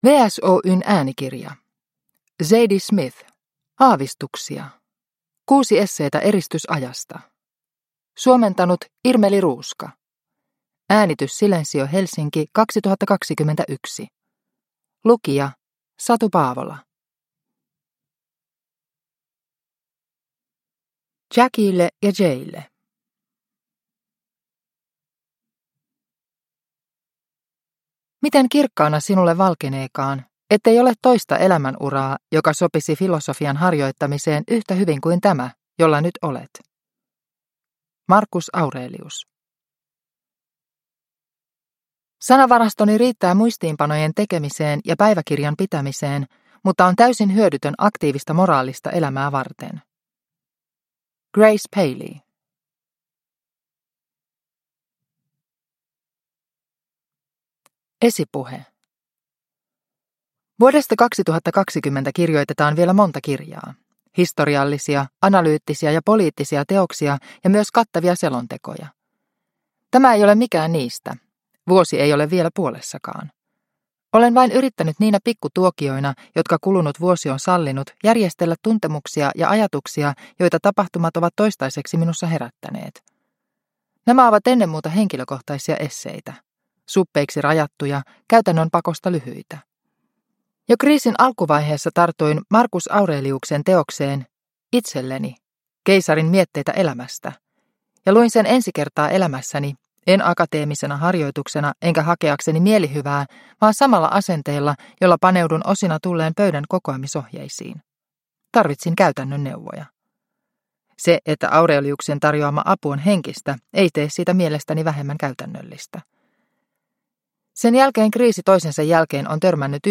Aavistuksia – Ljudbok – Laddas ner